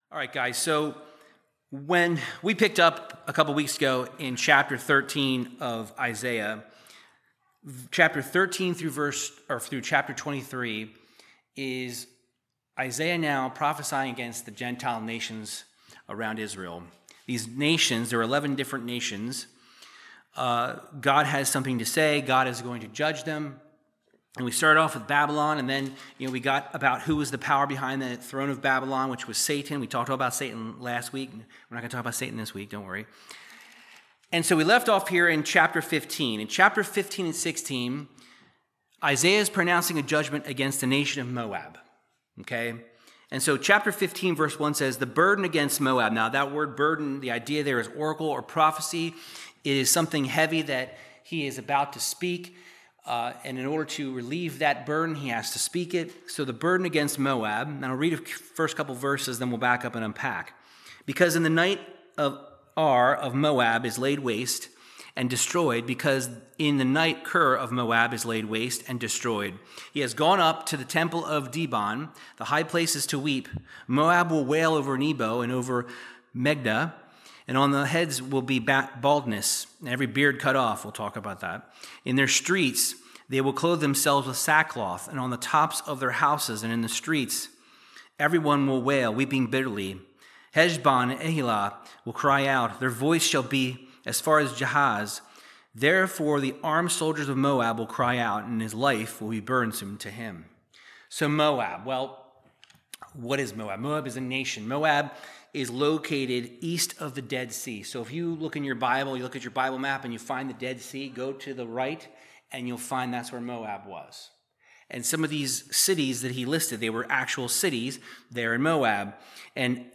Verse by verse Bible teaching through the book of Isaiah chapters 15 through 17